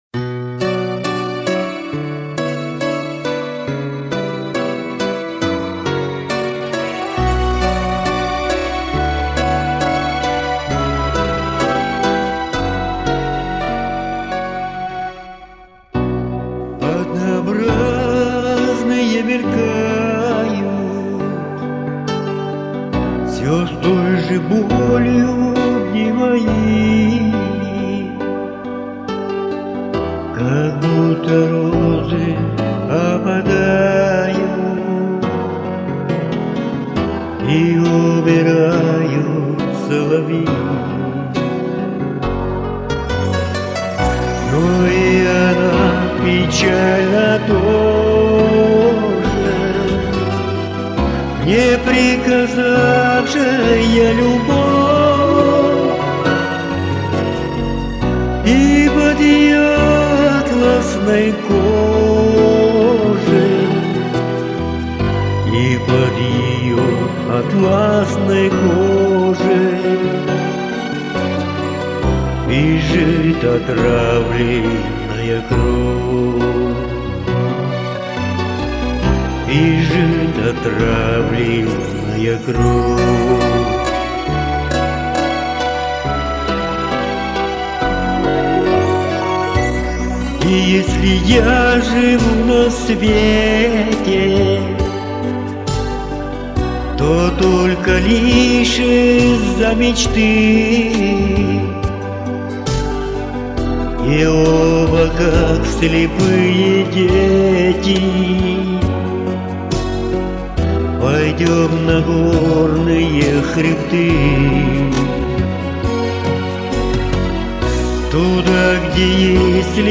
чистил?Остался металлический призвук..